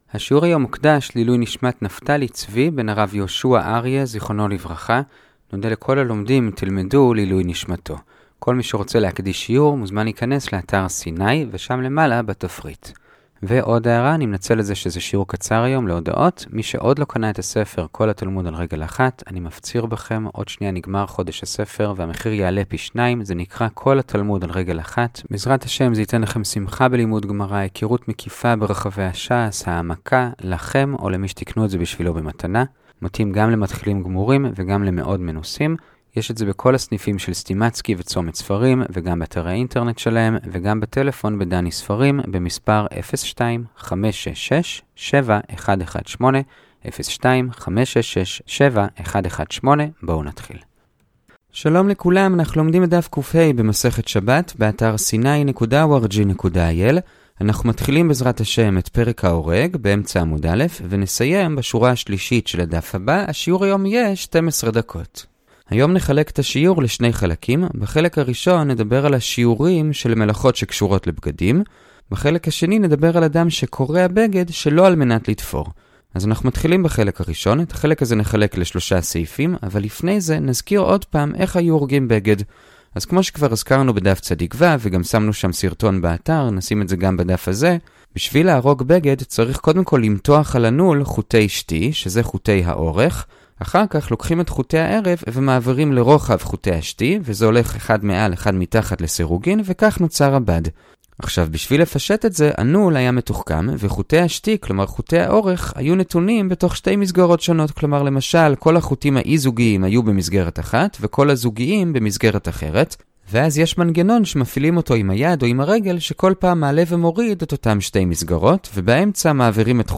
הדף היומי - שבת דף קה - הדף היומי ב15 דקות - שיעורי דף יומי קצרים בגמרא